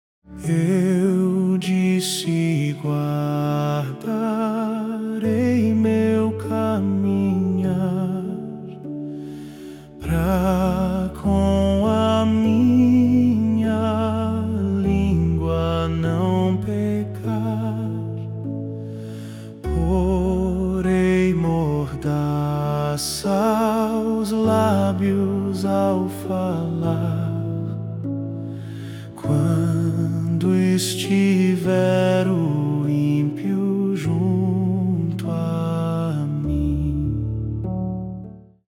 salmo_39B_cantado.mp3